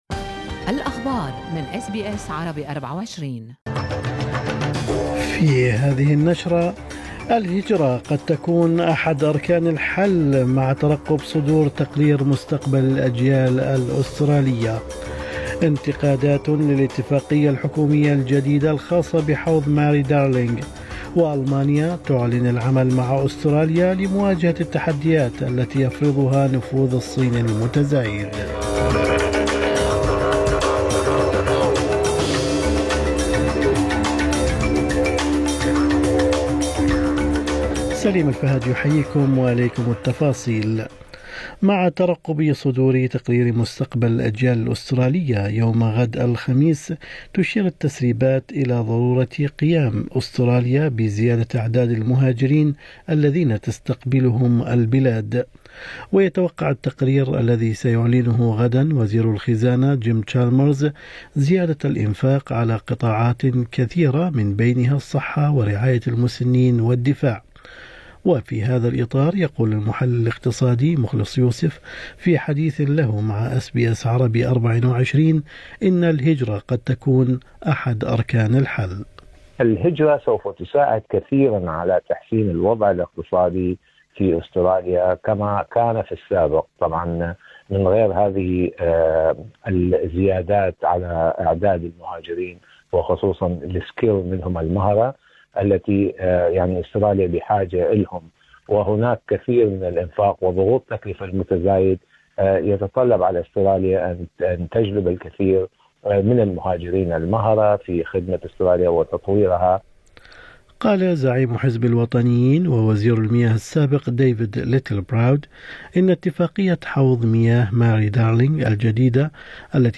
نشرة أخبار الصباح 23/8/2023